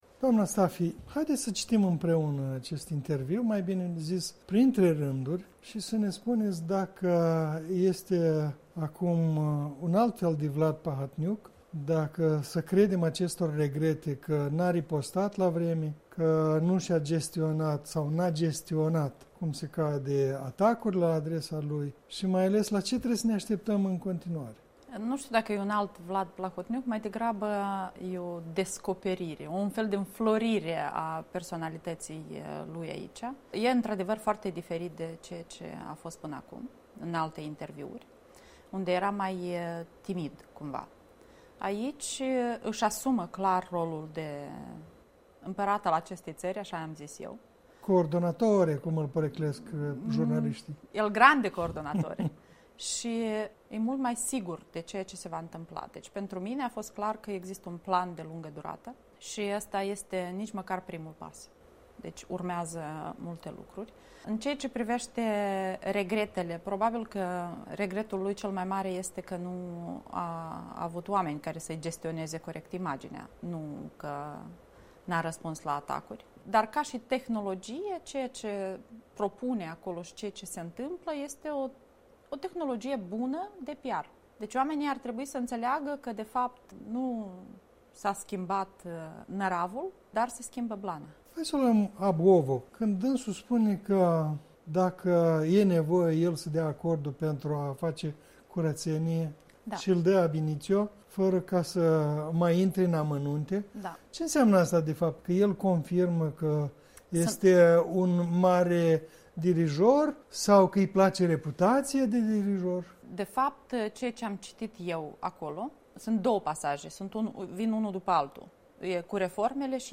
O analiză pe text a unui interviu.